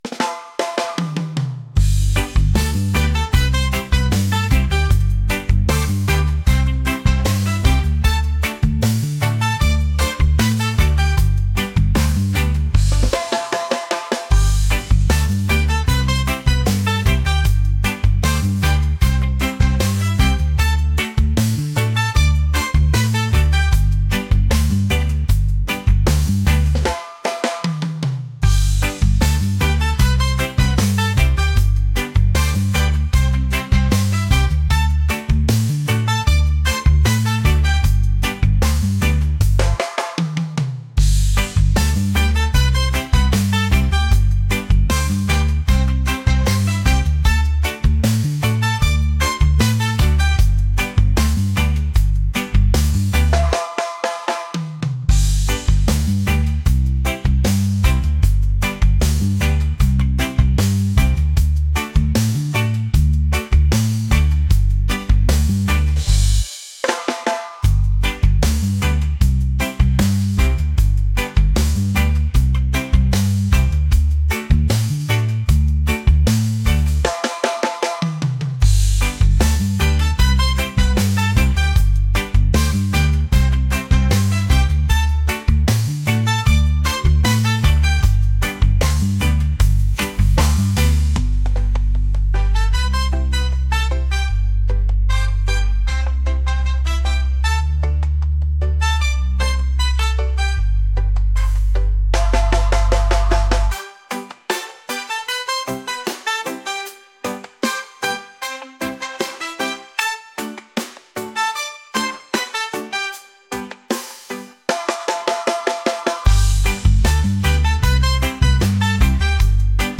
upbeat | reggae | groovy